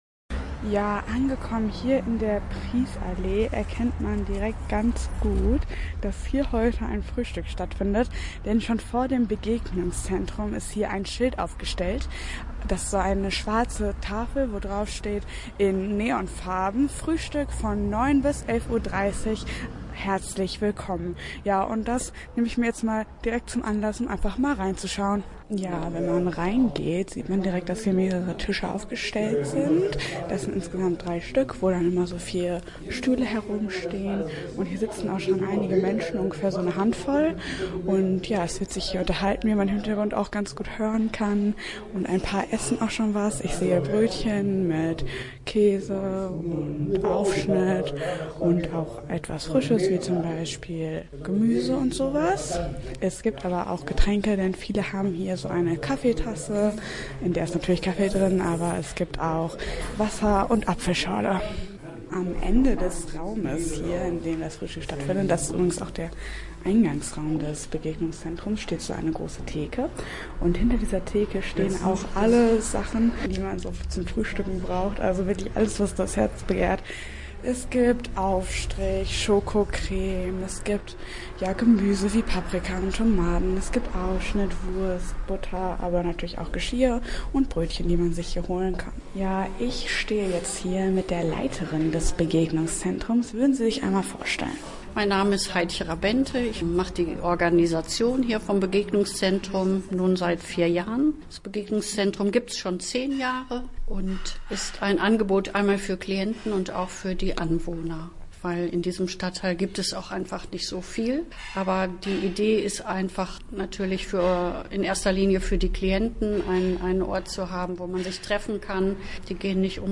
im Begegnungszentrum Prießallee, im Osten von Bielefeld. Dort können Menschen mit und ohne Einschränkungen gemeinsam frühstücken.